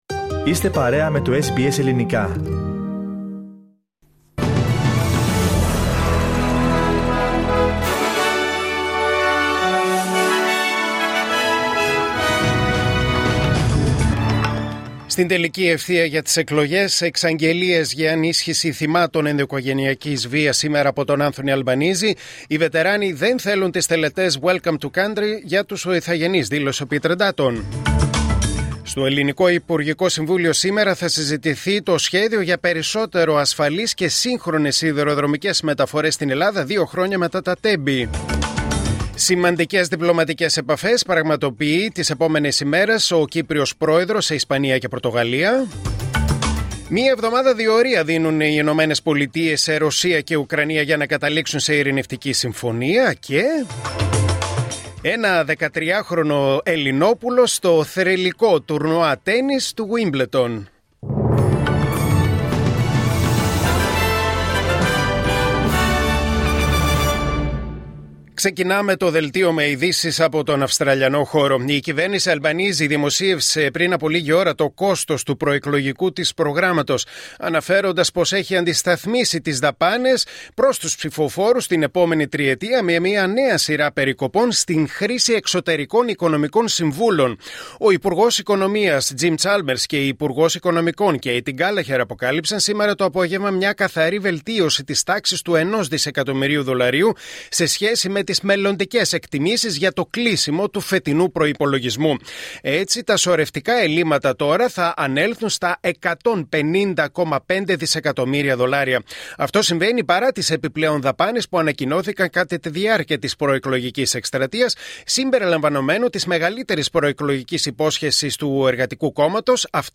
Δελτίο Ειδήσεων Δευτέρα 28 Απριλίου 2025